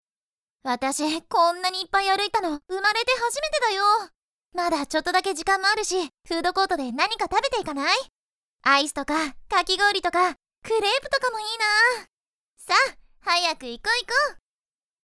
With a bright and easy listening voice, specialize in high-tension advertising videos.
– Voice Actor –
Energetic junior high school girls